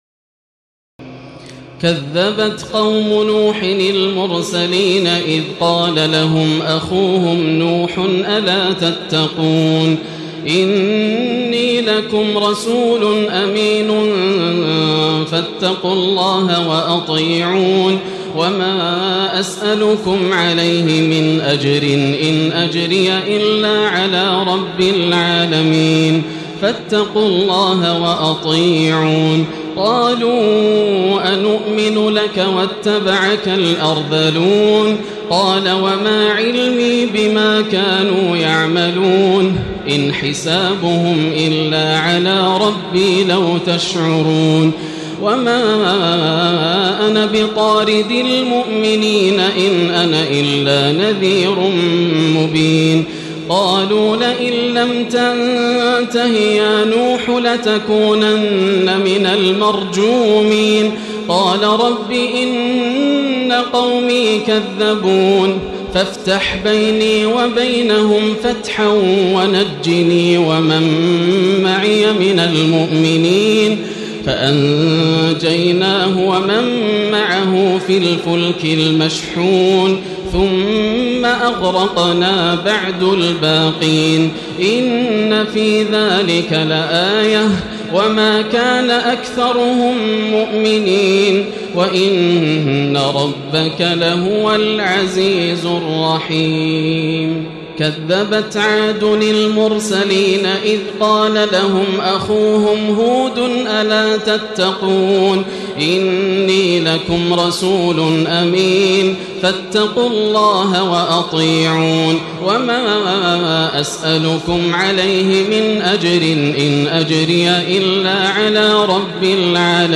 تراويح الليلة الثامنة عشر رمضان 1436هـ من سورتي الشعراء (105-227) والنمل (1-58) Taraweeh 18 st night Ramadan 1436H from Surah Ash-Shu'araa and An-Naml > تراويح الحرم المكي عام 1436 🕋 > التراويح - تلاوات الحرمين